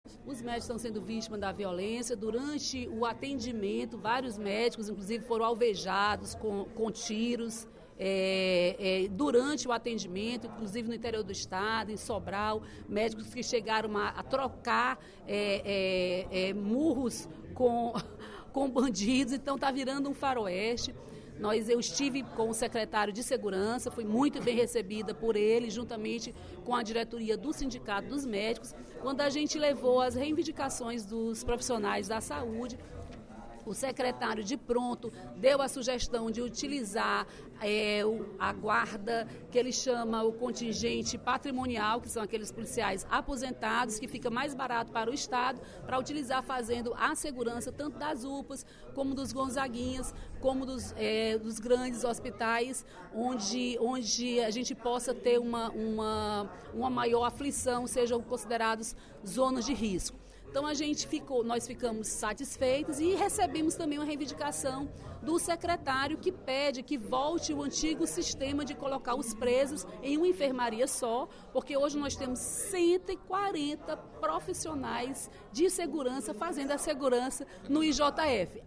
A deputada Dra. Silvana (PMDB) informou, durante o primeiro expediente da sessão plenária desta terça-feira (06/06), ter cobrado do Estado mais segurança para as unidades de saúde do Estado. Segundo ela, os profissionais que trabalham nos hospitais e Unidades de Pronto Atendimento (UPAs) estão sendo alvo de violência.
Em aparte, Capitão Wagner (PR) avaliou que não deveria haver a escolta de presos pelos policiais.